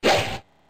Âm thanh tiếng Bắn Cung Tên
Thể loại: Đánh nhau, vũ khí
Description: Bạn đang nghe là âm thanh tiếng bắn cung tên, cung tên lao vun vút đi trong không khí, thể hiện tốc độ nhanh của cung tên cũng như dự báo lực sát thương cao. Đây là âm thanh của vũ khí, hiệu ứng tiếng bay có tốc độ cao, có thể sử dụng trong những phim cổ đại có cảnh đánh nhau.
tieng-ban-cung-ten-www_tiengdong_com.mp3